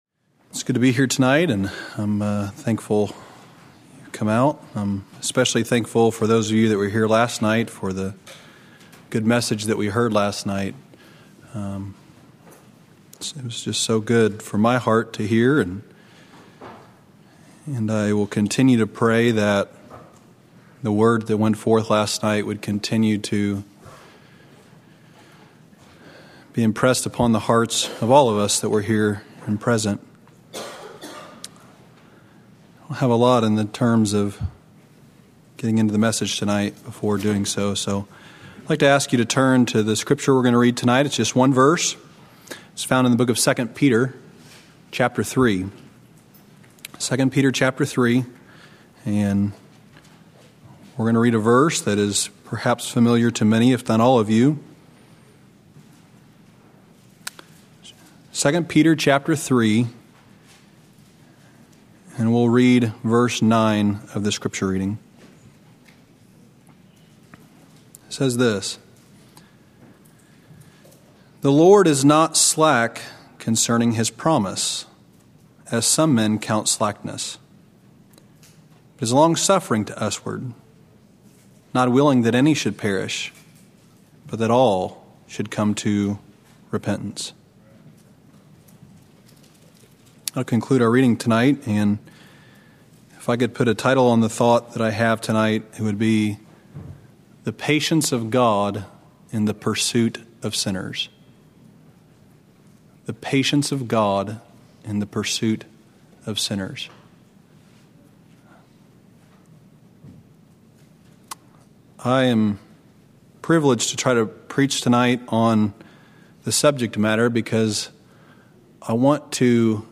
From Series: "Revival Sermons"
Sermons preached during special evening services - usually evangelistic.